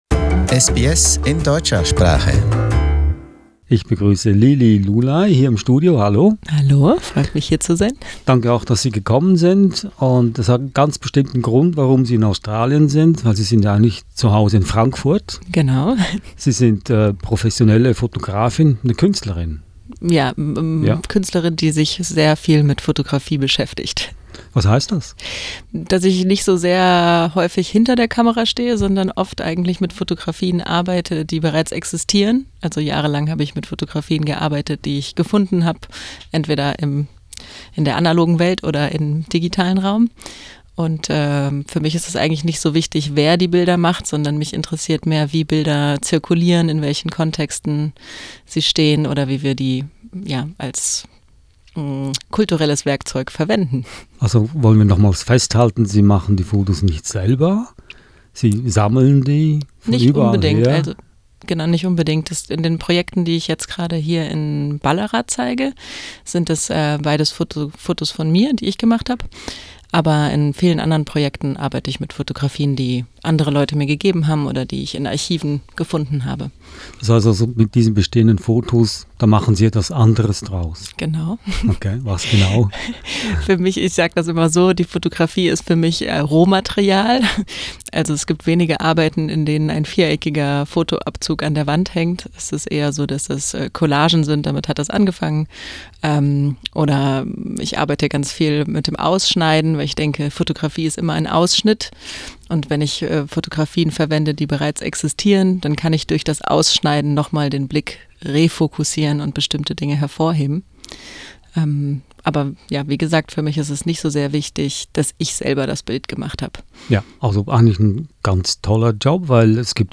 Im Studio sprach sie über ihre Kunst und darüber, was das Fotografieren im heutigen digitalen Zeitalter für sie bedeutet.